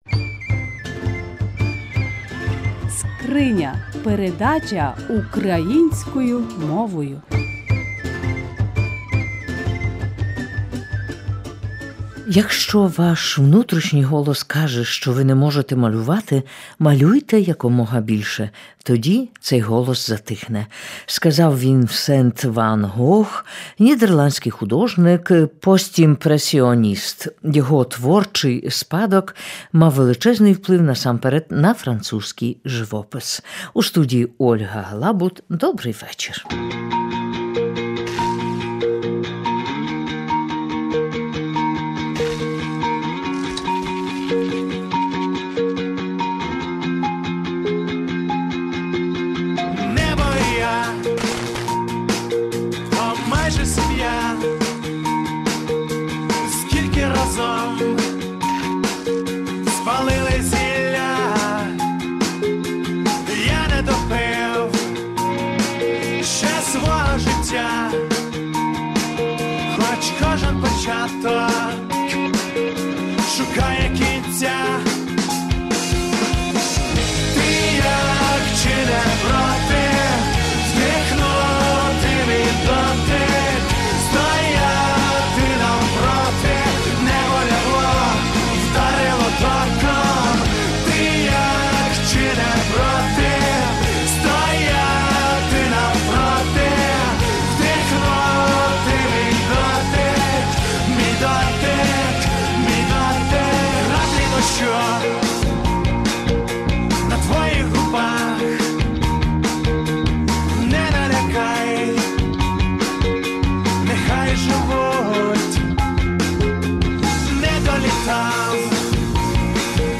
Прослухайте репортаж Жіноча Доля.